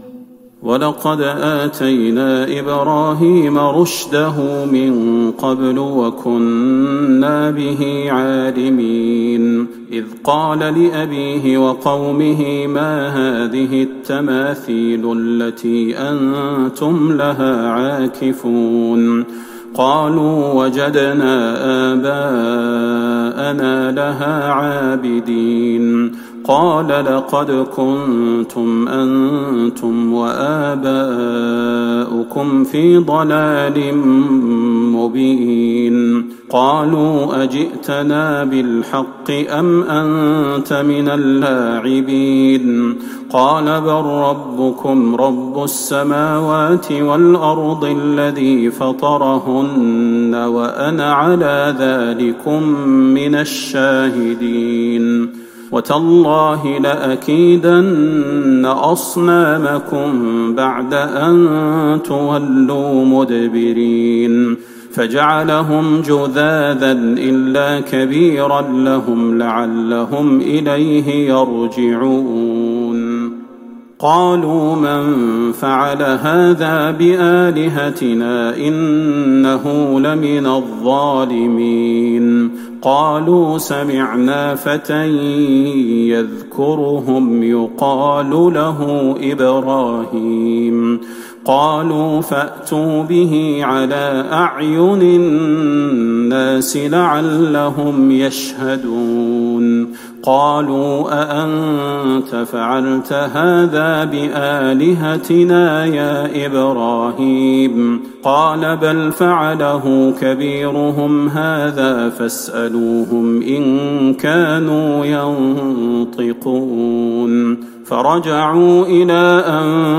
تهجد ٢١ رمضان ١٤٤١هـ من سورة الأنبياء { ٥١-١١٢ } والحج { ١-١٦ } > تراويح الحرم النبوي عام 1441 🕌 > التراويح - تلاوات الحرمين